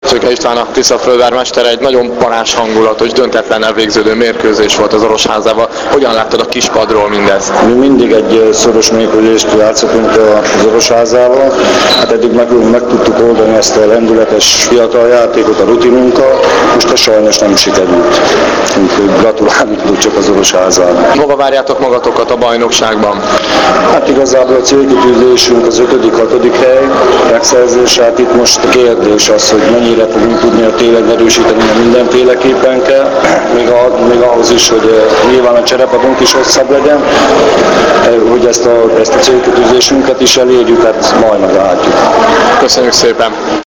Az interjú mp3-ban >>>